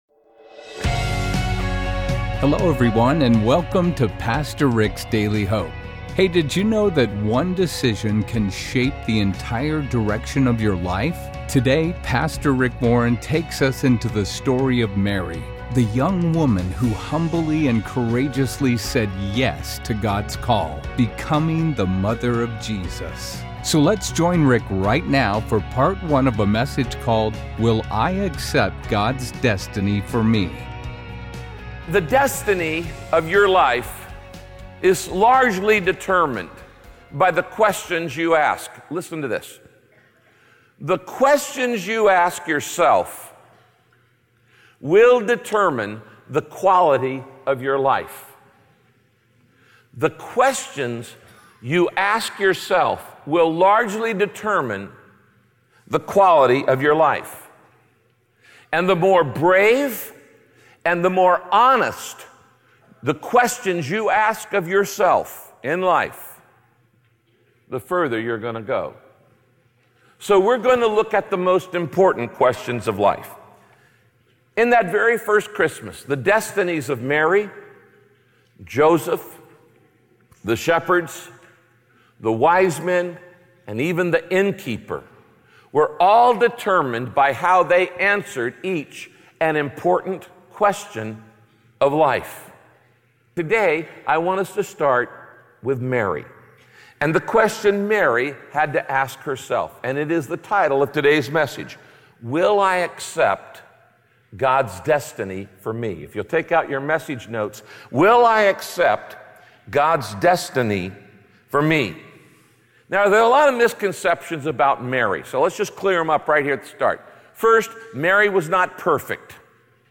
God's destiny for your life isn't always the easiest path—but it is always the best! In this teaching, Pastor Rick reminds you that your Creator knows and loves…